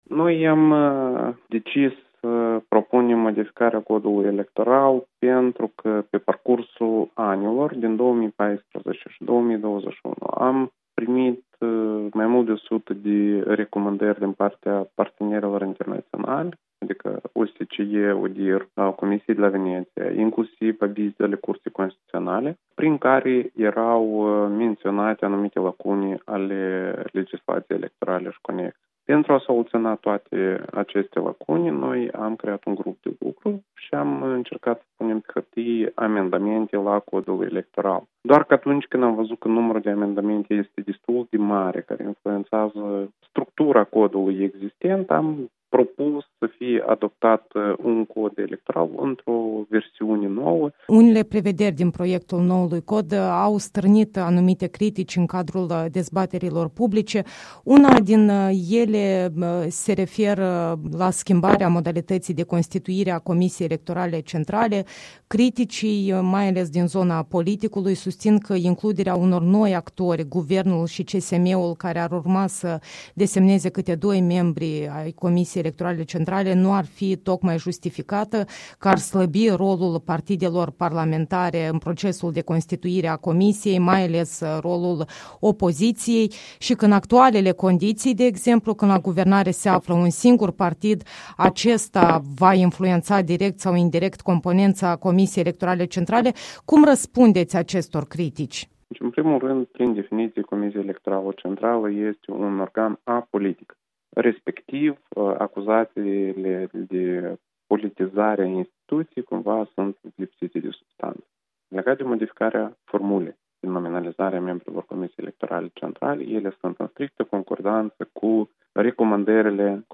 Interviu cu Pavel Postica, vicepreședintele CEC